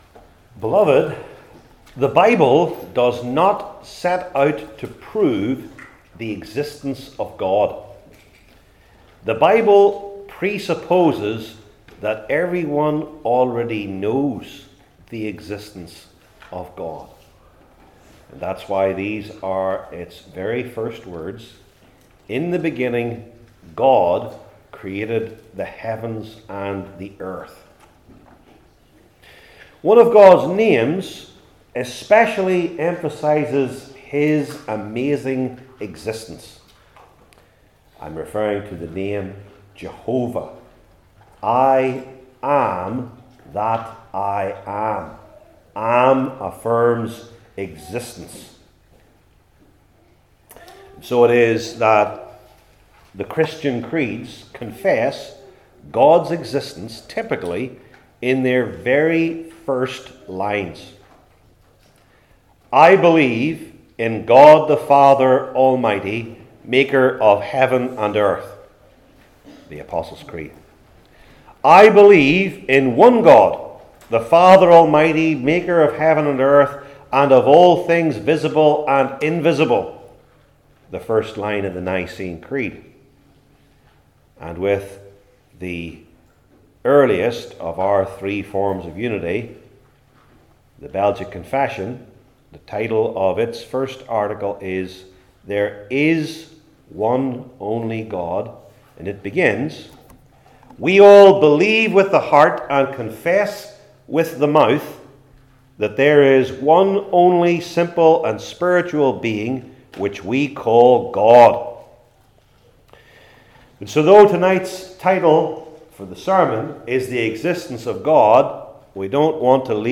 Romans 1:18-21 Service Type: New Testament Individual Sermons I. The Universal Witness II.